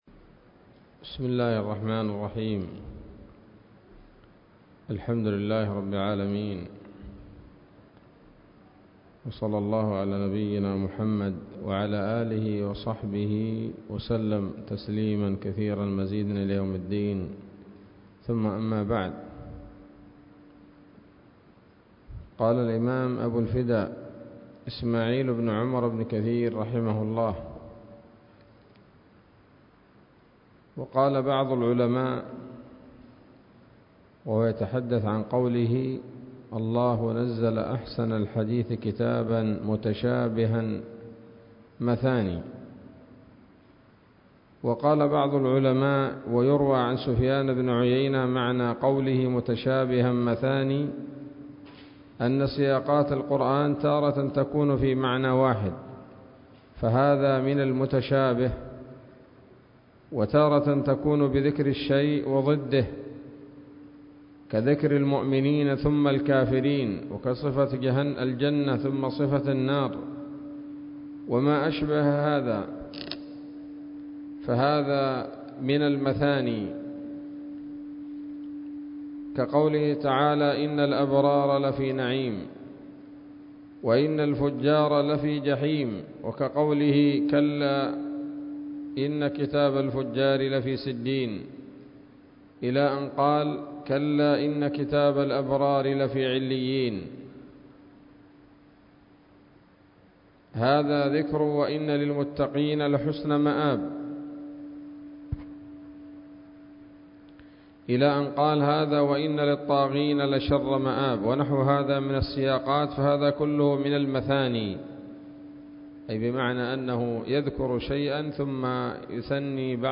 الدرس السابع من سورة الزمر من تفسير ابن كثير رحمه الله تعالى